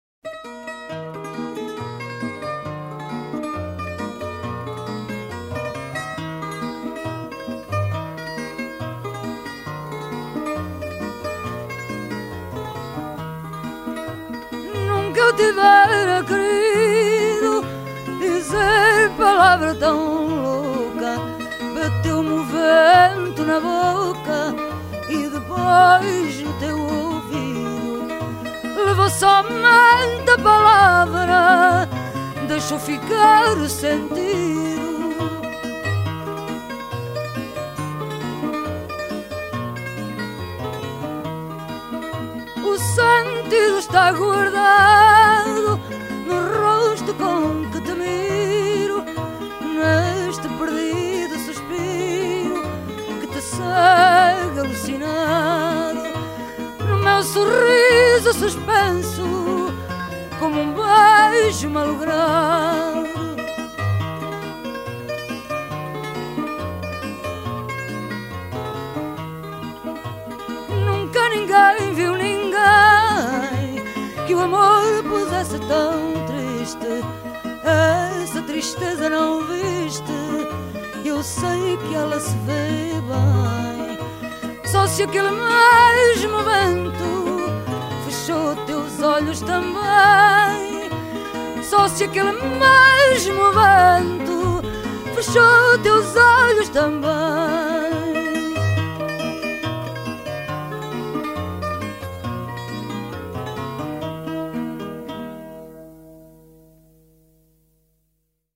from → Fado
guitare portugaise
guitare classique.